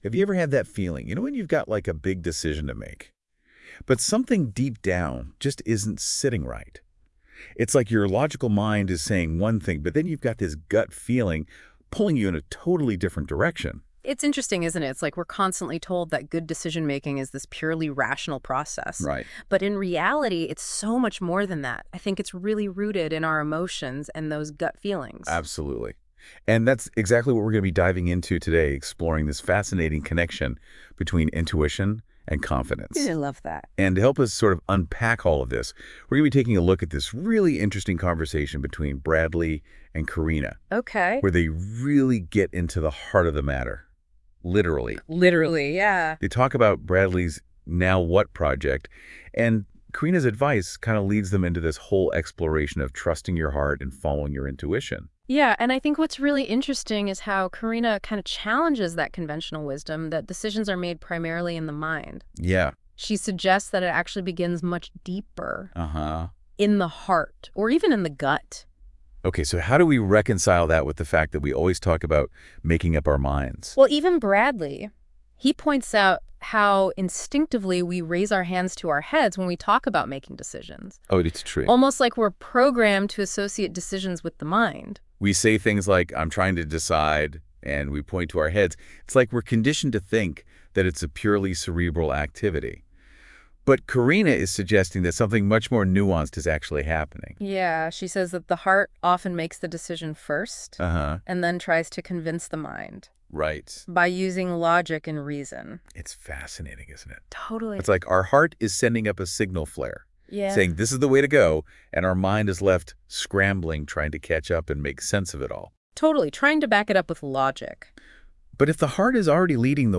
The speakers share personal anecdotes to illustrate the importance of trusting oneself.